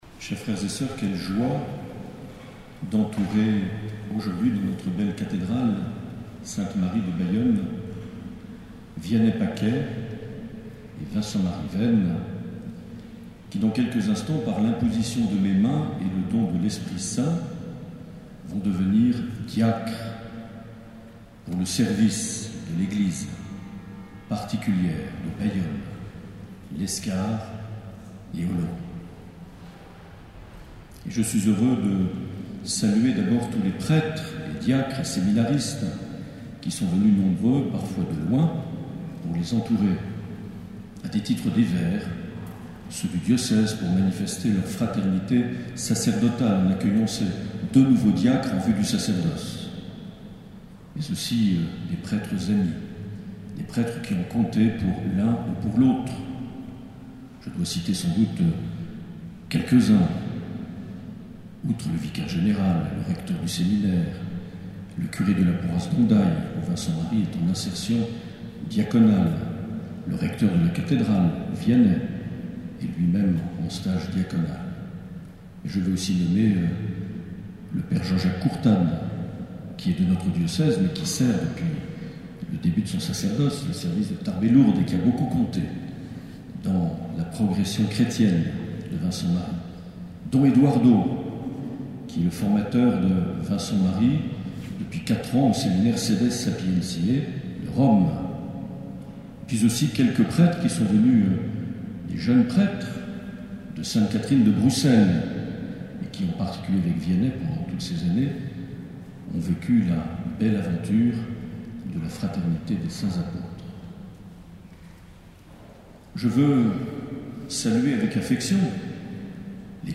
26 novembre 2017 - Cathédrale de Bayonne - Messe d’ordinations diaconales
Accueil \ Emissions \ Vie de l’Eglise \ Evêque \ Les Homélies \ 26 novembre 2017 - Cathédrale de Bayonne - Messe d’ordinations (...)
Une émission présentée par Monseigneur Marc Aillet